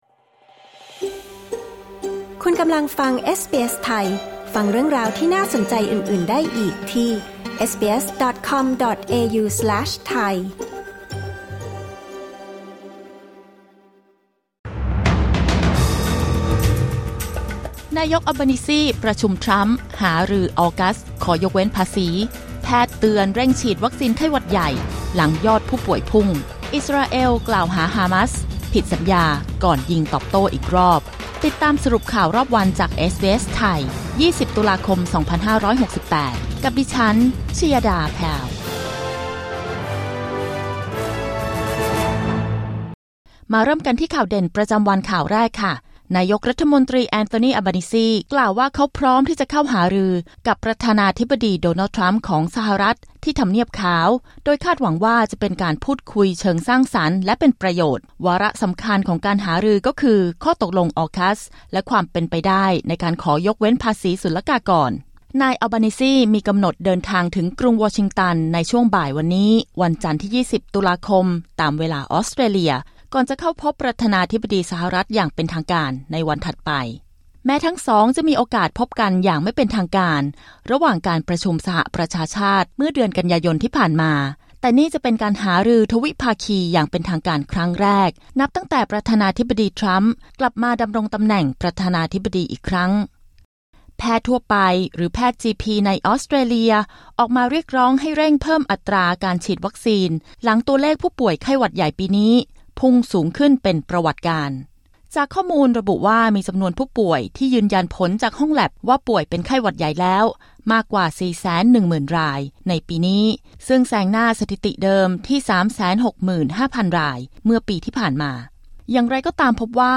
สรุปข่าวรอบวัน 20 ตุลาคม 2568